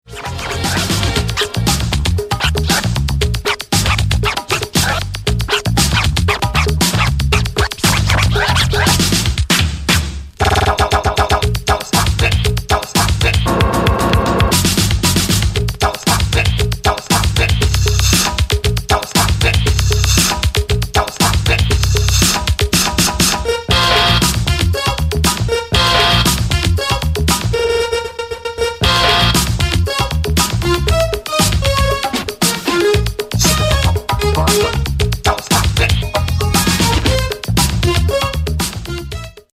ディスコ／オールド・スクール・ファン、そしてメガ・ミックスからカットアップ、